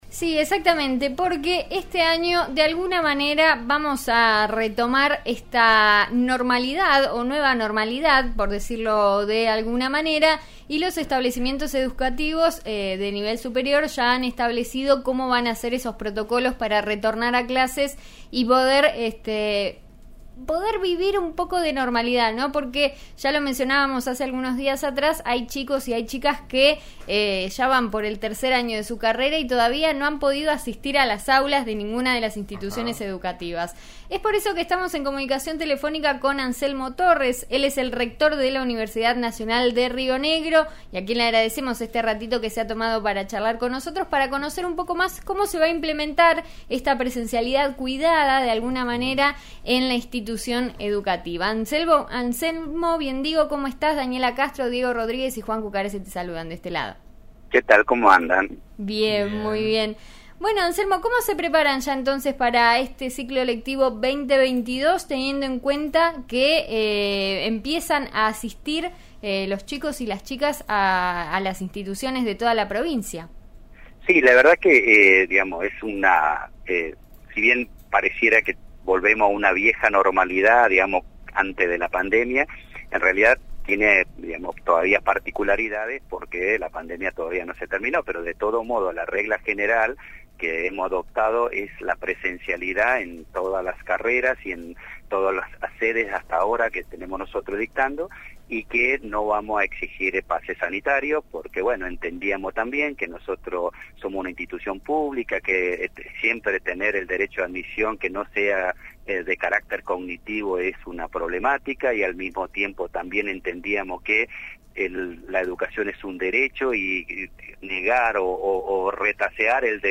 El rector Anselmo Torres dialogó con En eso estamos de RN Radio (89.3) y dio detalles sobre cómo será el regreso a clases. Escuchá la entrevista completa: